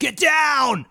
Voices / Male / Get Down.wav
Get Down.wav